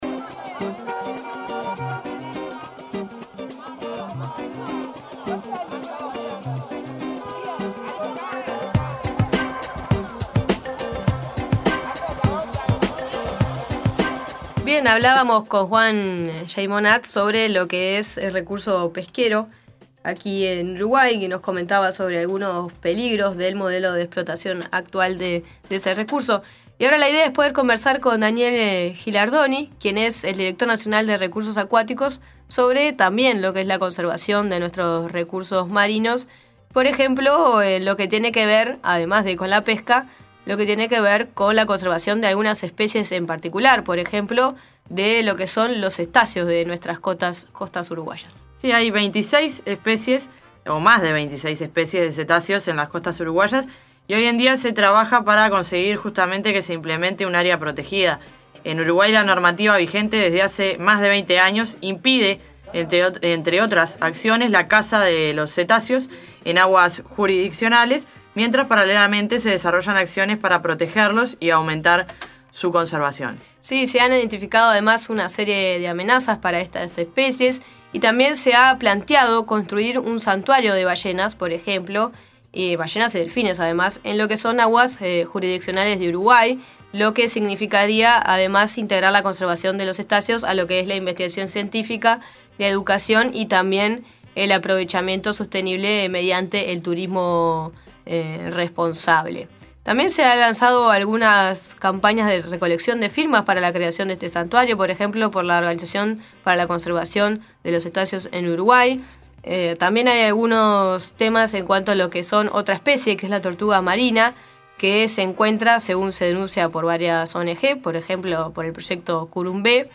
En La Nueva Mañana seguimos indagando sobre el cuidado de los recursos acuáticos en nuestro mar territorial. Entrevistamos a Daniel Gilardoni, director Nacional de Recursos Acuáticos, sobre las políticas de protección y también sobre el plan de manejo para la conservación de la ballena franca austral en el Océano Atlántico Sudoccidental, que identificó posibles amenazas a estas poblaciones.